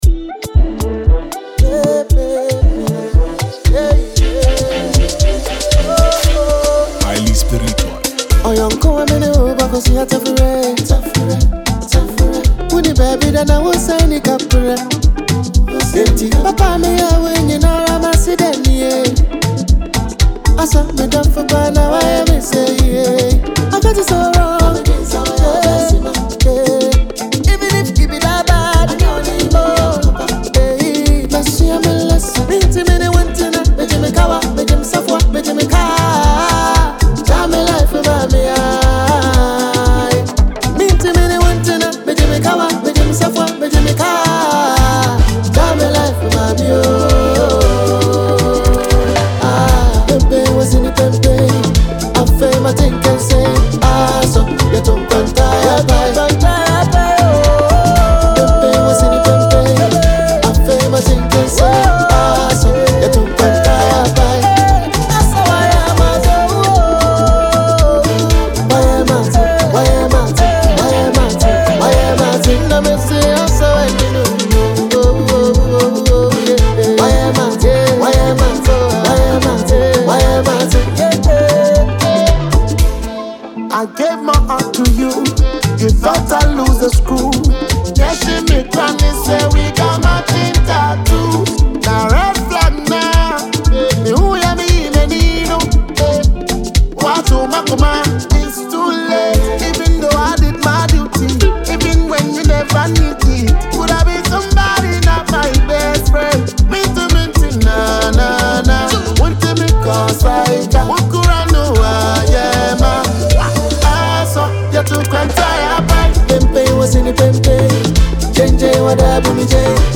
” mixing smooth vocals with modern Afrobeat rhythms.